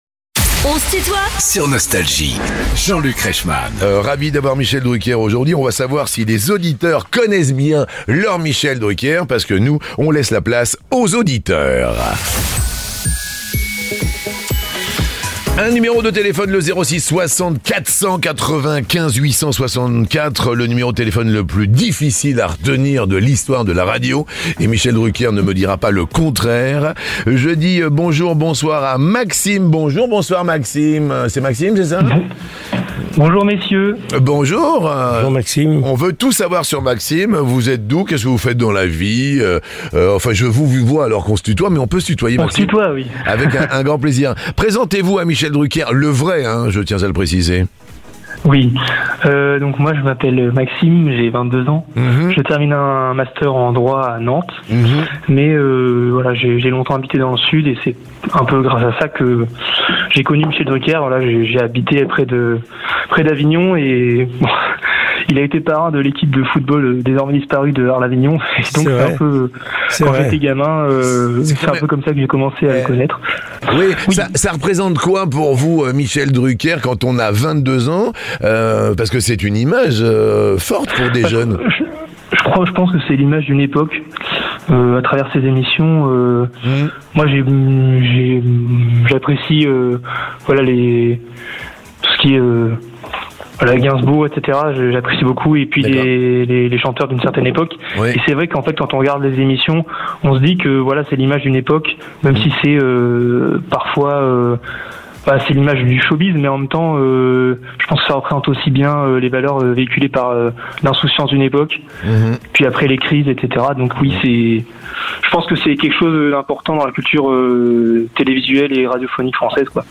Michel Drucker est l'invité de "On se tutoie ?..." avec Jean-Luc Reichmann (partie 2) ~ Les interviews Podcast